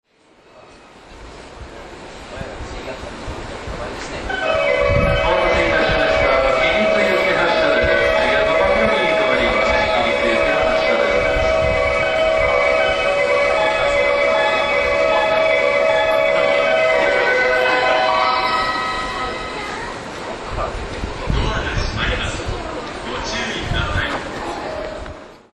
発車メロディーの音量が小さい上に駅員さんが喋りまくるので収録は困難です。
Gota del Vient こちらは比較的音量が大きいのですが…